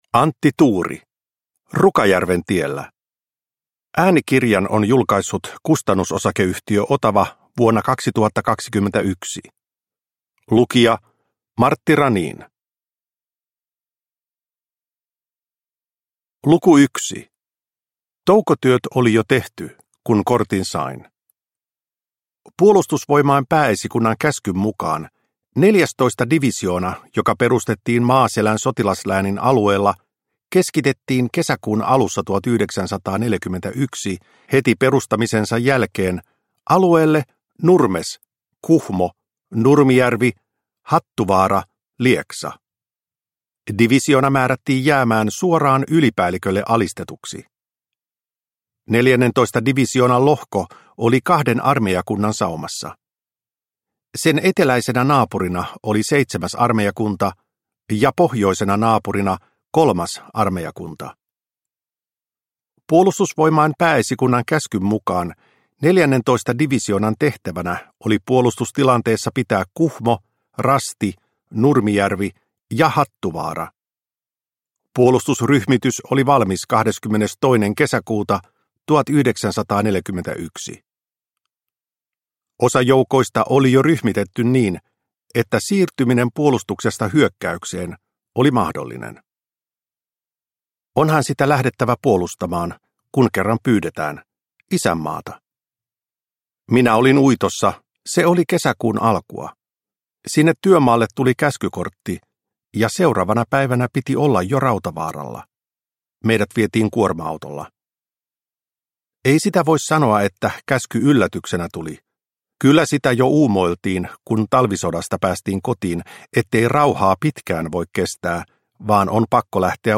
Rukajärven tiellä – Ljudbok – Laddas ner